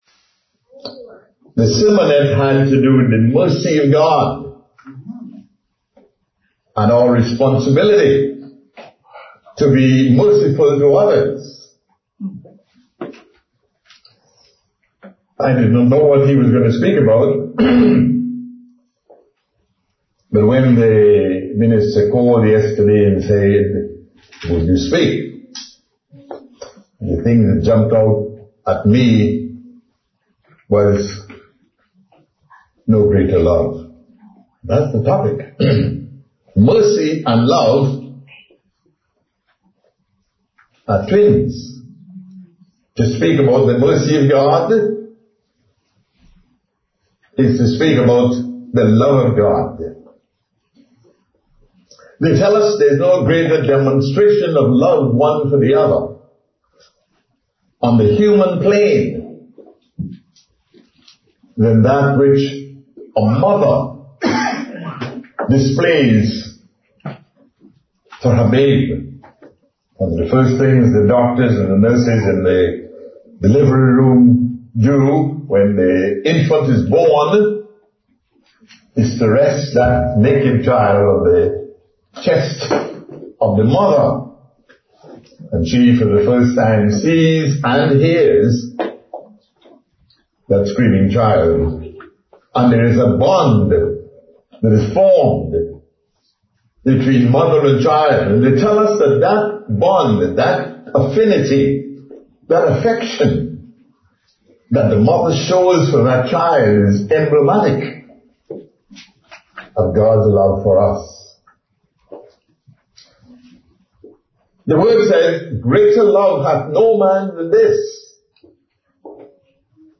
The love of God cannot be spoken of enough, the sermon examines the most popular verse in Christianity. The spirit of God is to distribute that love in us. Are we exhibiting that love as children of God?